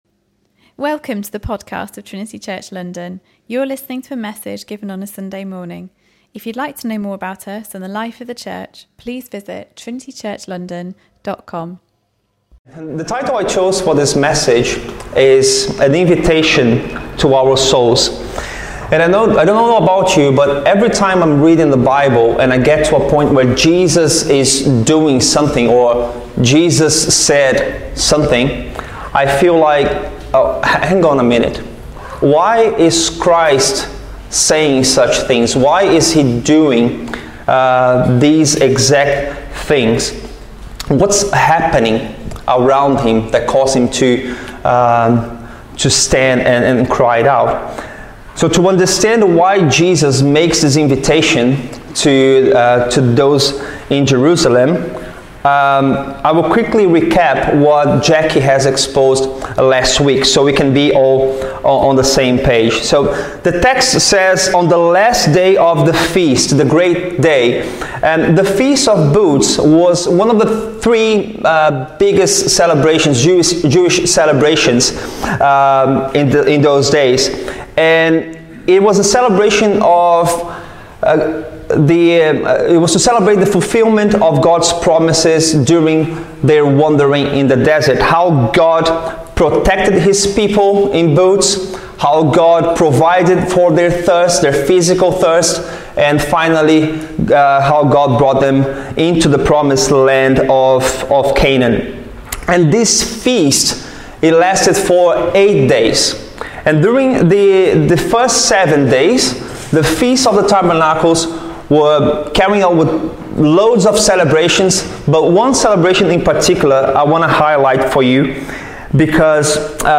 Jesus asks that all who are thirsty should come to Him and drink, and He promises that whoever believes in Him, as Scripture has said, rivers of living water will flow from within them. In this Sermon